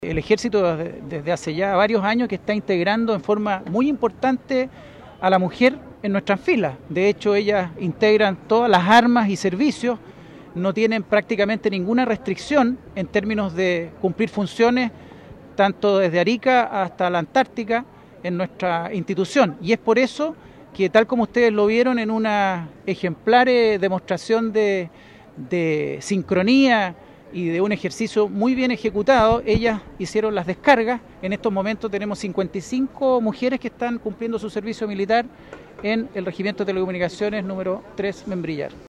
Más de un centenar de militares realizaron el juramento a la bandera en la Plaza de la República de Valdivia.
En ese sentido, el General de la Tercera División de Montaña del Ejército, Joaquín Morales Burotto reconoció la presencia femenina en las filas de la institución castrense y precisó que 55 mujeres realizan su servicio militar en el regimiento de Valdivia.